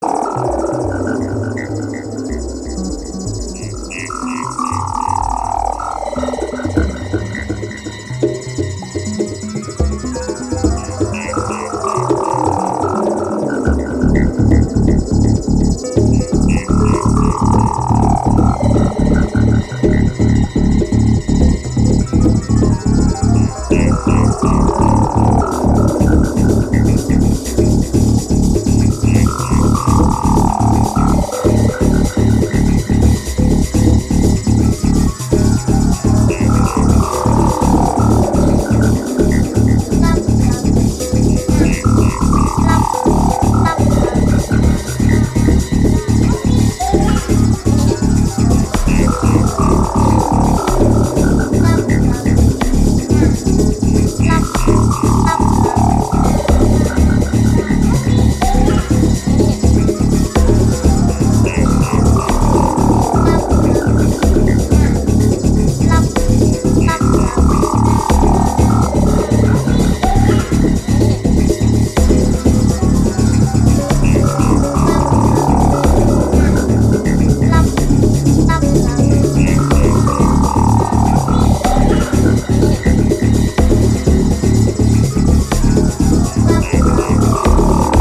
メディテーティヴなサイケデリック・トラック